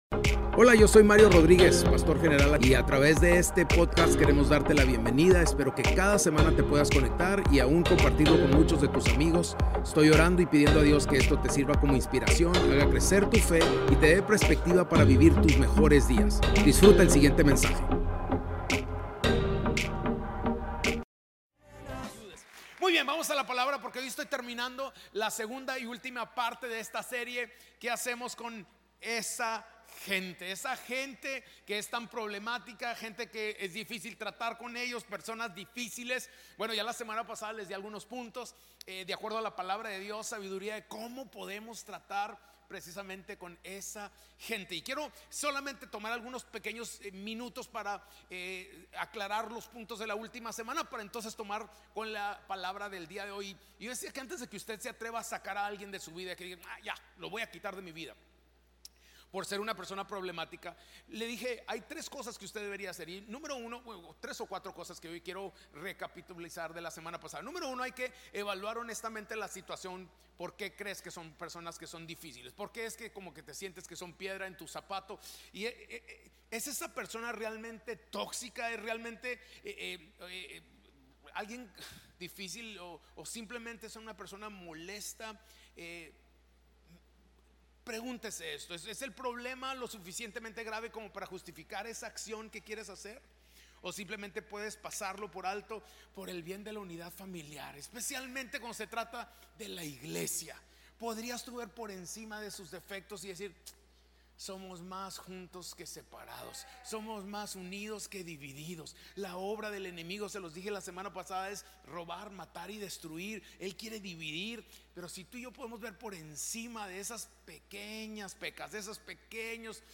Spanish Sermons